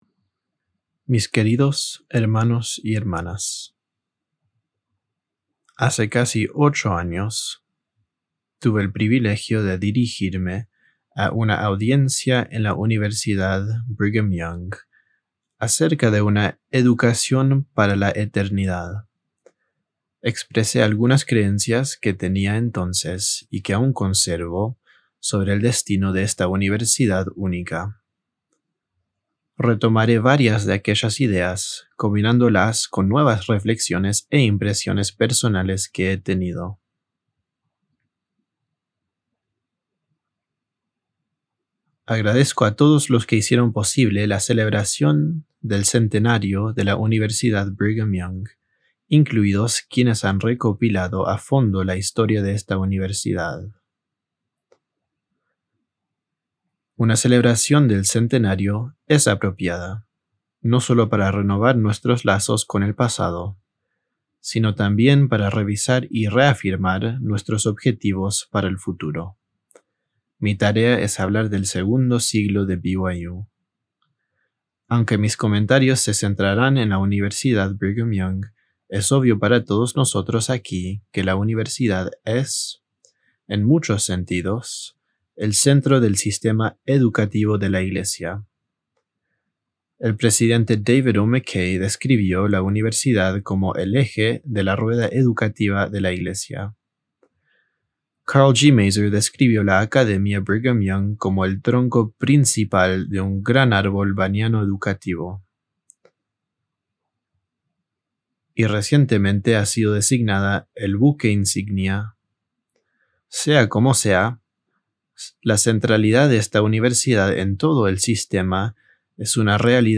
Audio recording of El segundo siglo de la Universidad Brigham Young by Spencer W. Kimball